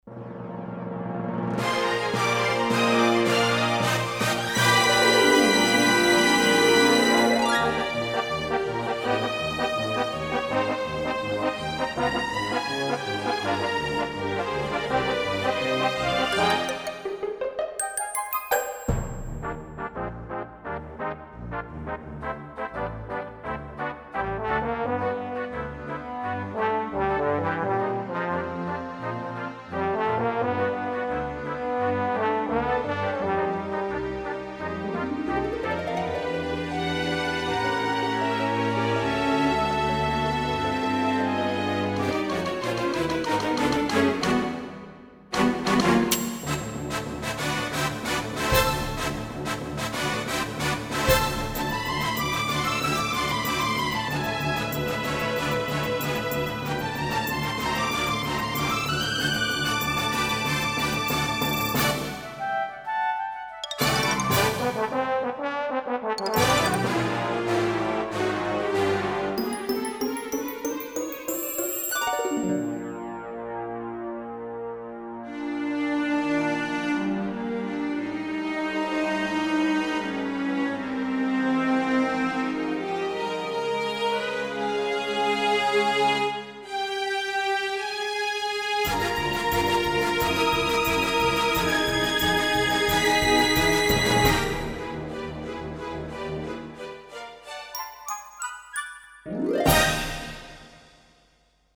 Orchestra module (1995)
factory demo 1